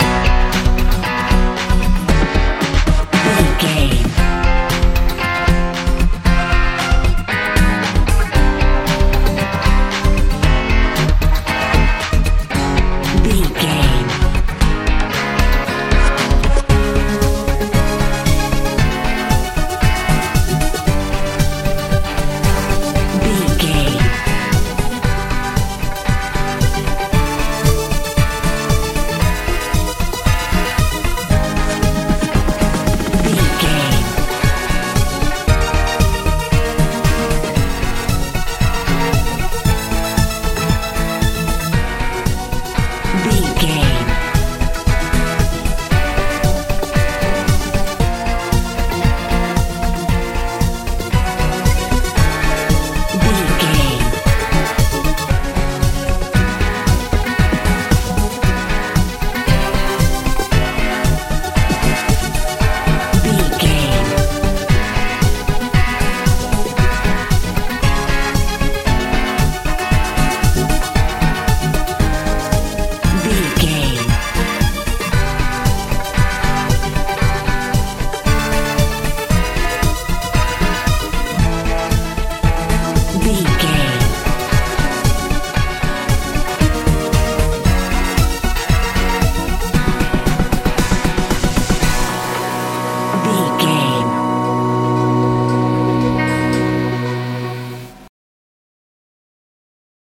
modern dance country
Ionian/Major
ethereal
fun
smooth
electric guitar
synthesiser
bass guitar
drums
80s
90s